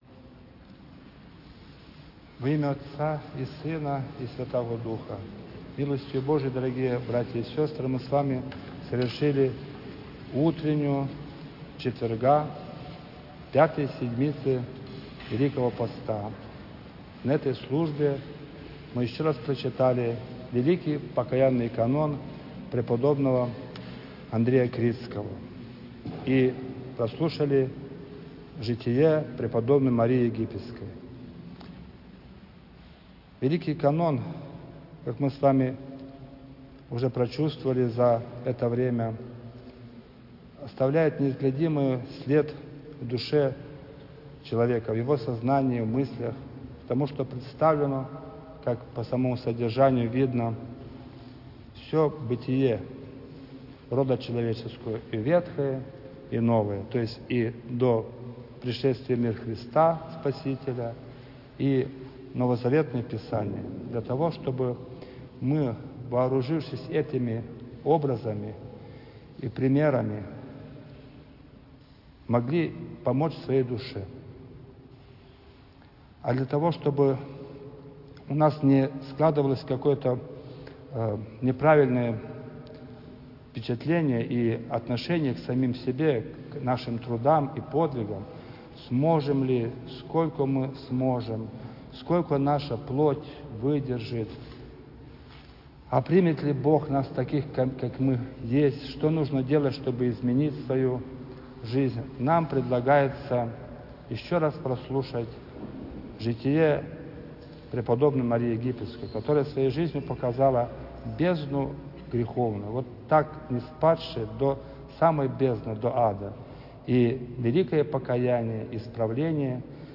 Проповедь
В четверг, 26 марта утром в храме святого праведного Иоанна Кронштадтского была совершена Литургия Преждеосвященных Даров.